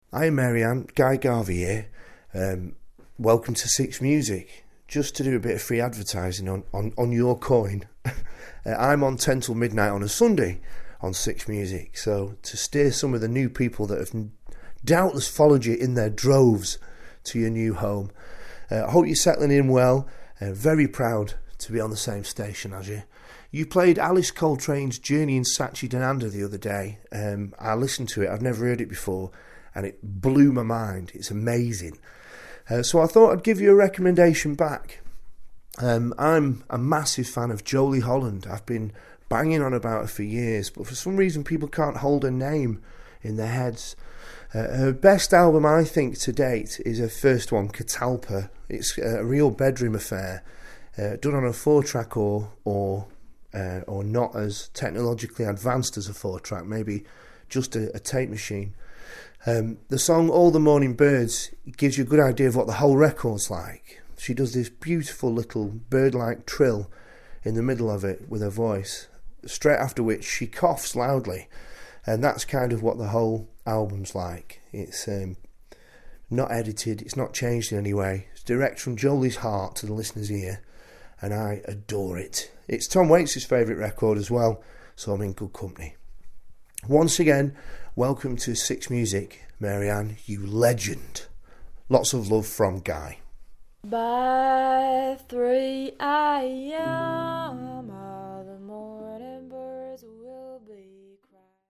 From one 6 Music presenter to another ... Guy Garvey sends Mary Anne Hobbs an aural postcard, welcoming her to her new home. Plus he makes a fantastic, underground musical recommendation.